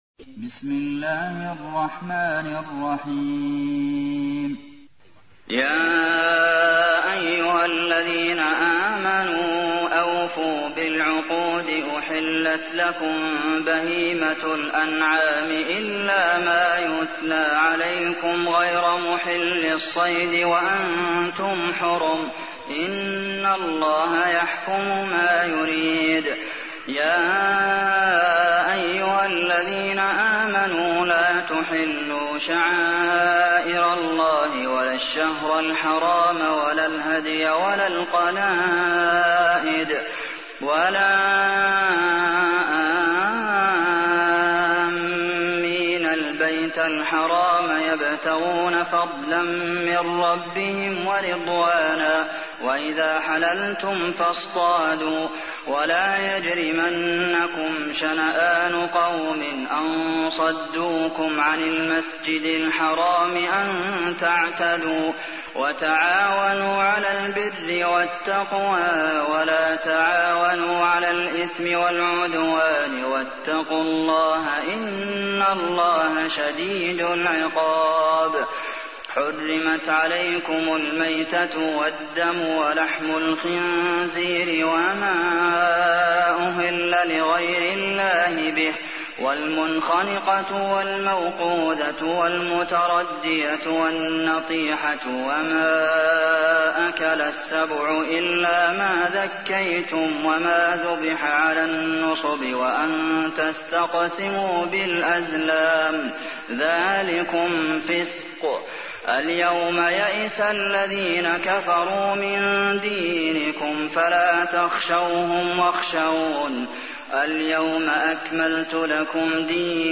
تحميل : 5. سورة المائدة / القارئ عبد المحسن قاسم / القرآن الكريم / موقع يا حسين